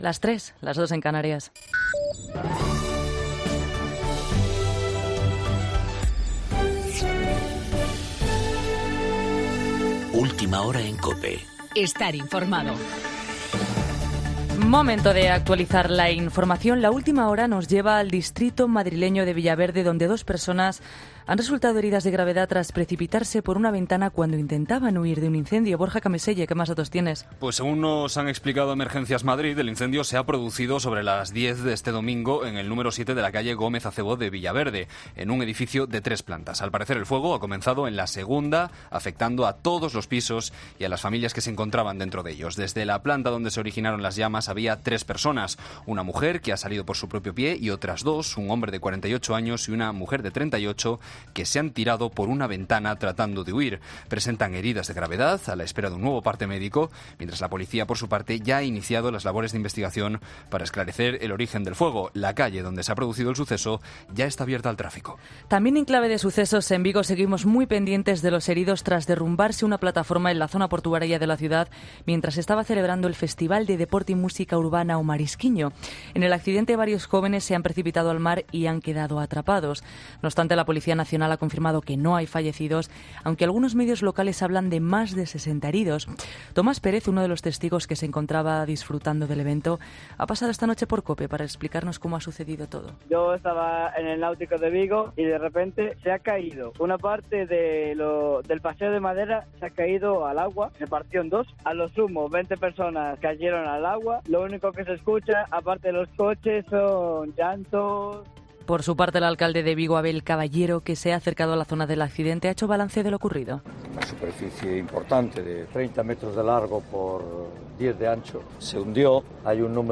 Boletín Informativo de las 3.00, 13 de agosto de 2018